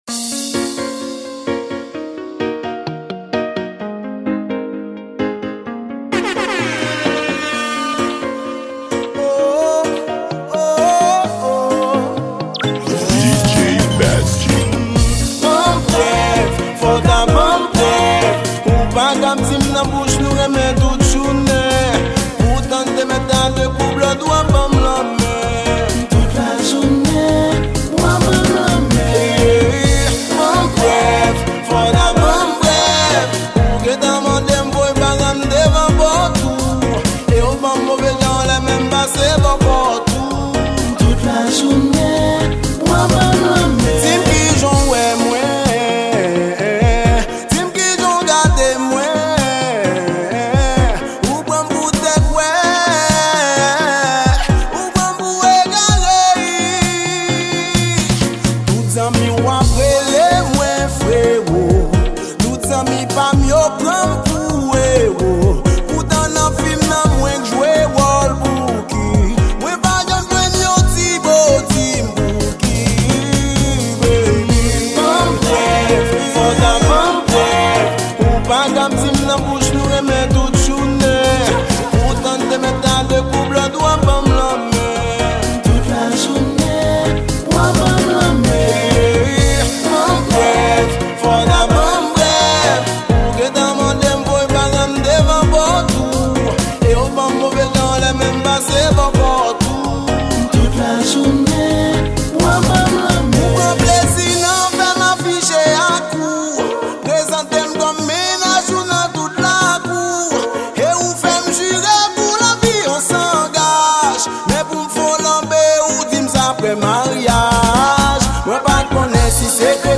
Genre: REGGEA.